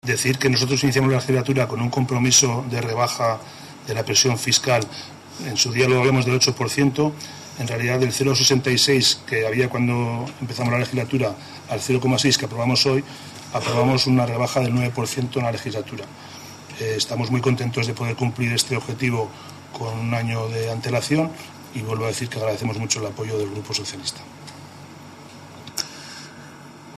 El pleno del Ayuntamiento de Haro ha acordado reducir la presión fiscal de los jarreros en un 4,76% del impuesto de bienes inmuebles. Una medida que entrará en vigor el 1 de enero de 2027 y que supone «una bajada de impuestos en la legislatura del 9%, según detallaba el concejal de Economía, Rafael García.
PLENO-IMPUESTOS-PP_.mp3